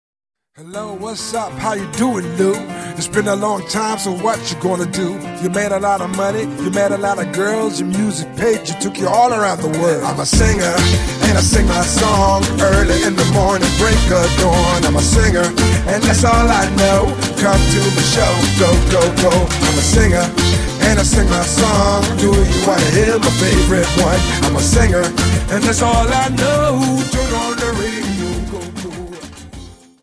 Dance: Samba 50s